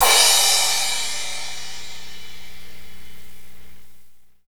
Index of /90_sSampleCDs/Northstar - Drumscapes Roland/CYM_Cymbals 3/CYM_P_C Cyms x